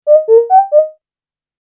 Synth Whistle Notification Sound Effect
Description: Synth whistle notification sound effect. Bright, clear, and catchy notification tone, perfect for mobile alerts, messages, and app notifications.
Synth-whistle-notification-sound-effect.mp3